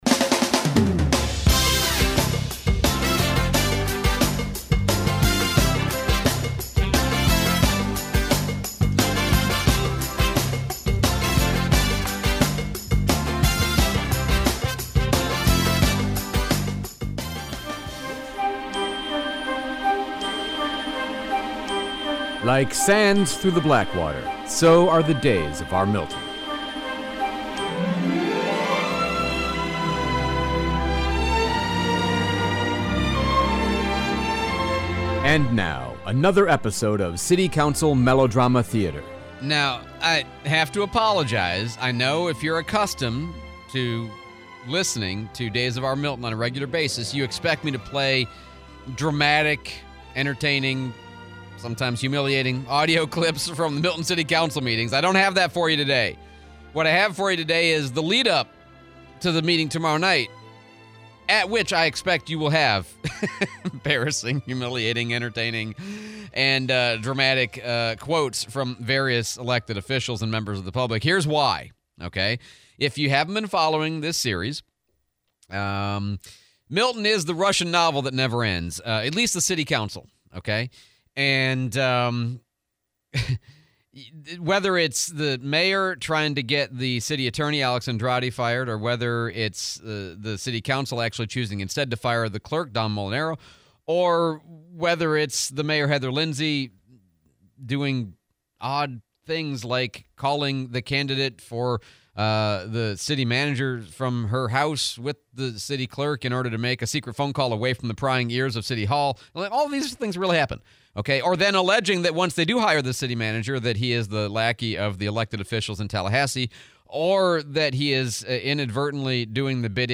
Days of our Milton: preview to tomorrow's meeting / Mississippi Goon Squad members sentenced to time in prison / Texas SB4 / Interview with Mayor D.C. Reeves